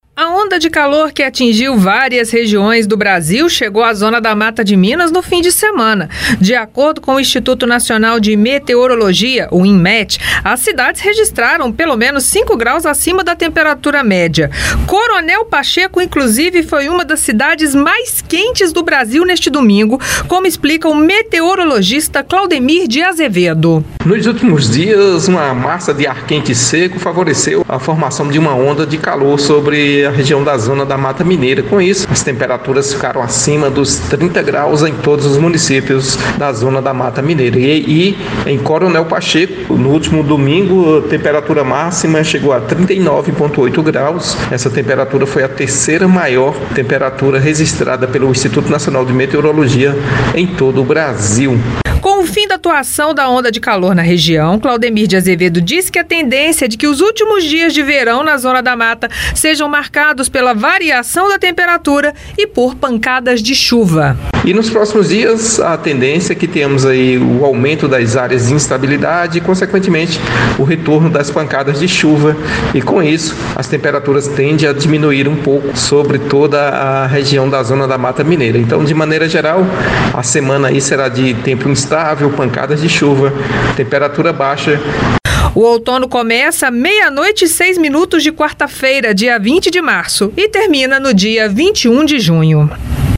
No entanto, a saideira do verão será marcada por tempo instável e pancadas de chuvas. Ouça a entrevista